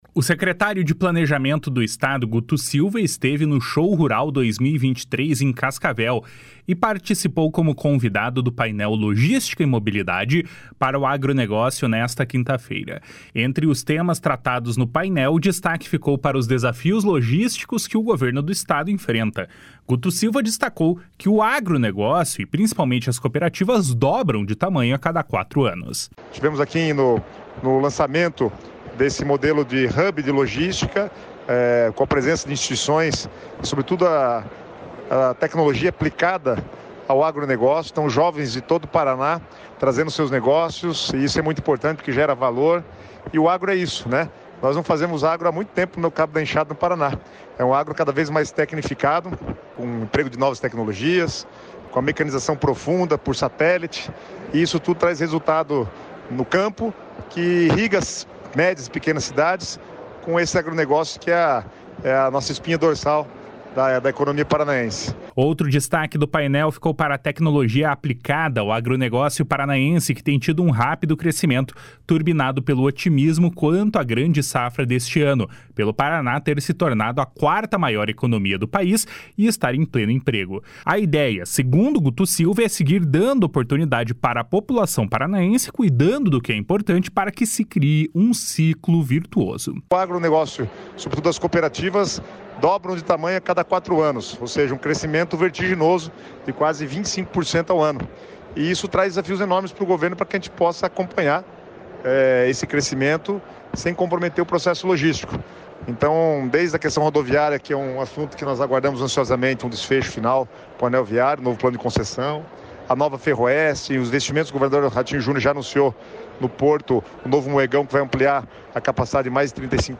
O secretário de Planejamento do Estado, Guto Silva, esteve no Show Rural 2023, em Cascavel, e participou como convidado do painel Logística e Mobilidade para o Agronegócio, nesta quinta-feira.
Guto Silva destacou que o agronegócio, e principalmente as cooperativas, dobram de tamanho a cada quatro anos. // SONORA GUTO SILVA //